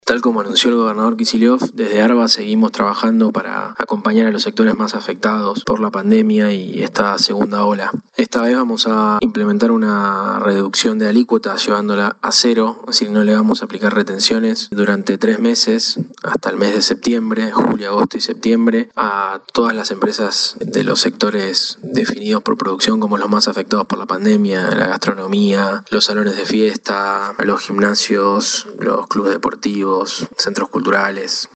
Así lo confirmó el titular de ARBA, Cristian Girard, quien destacó que estas iniciativas buscan acompañar el esfuerzo que están haciendo las y los bonaerenses para enfrentar y superar este difícil contexto: